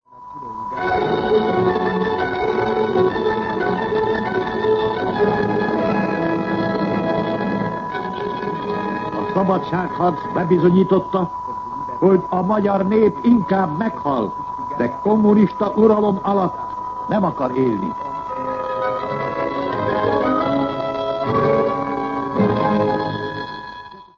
Jelmondatok 1956. október 27. 17:20 ● 00:24 ► Meghallgatom Műsor letöltése MP3 00:00 00:00 A műsor leirata Szignál A szabadságharc bebizonyította, hogy a magyar nép inkább meghal, de kommunista uralom alatt nem akar élni!